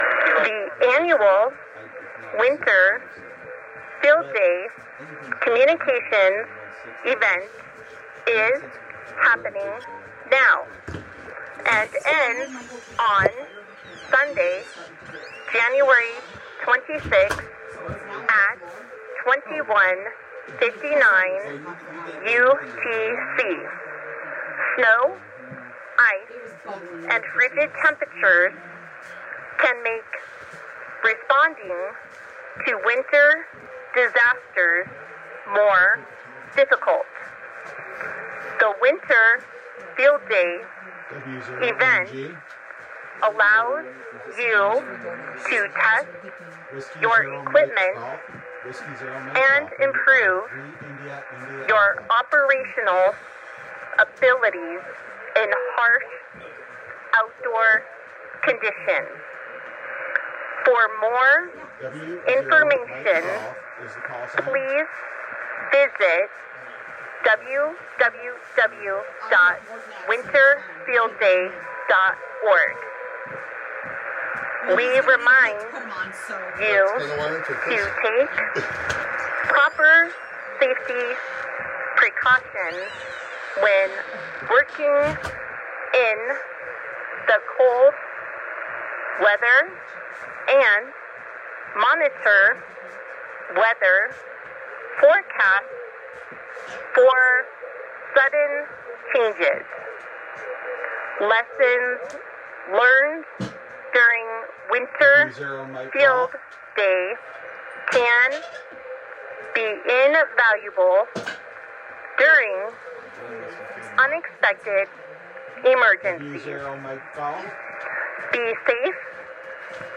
There was a bulletin for Winter Field Day, similar to the one done in the summer. It was broadcast on a schedule of
When read off over voice, the announcer sounds a bit slow and
mechanical, but that's just to give the operators a better chance to copy it down, since turning in a copy of the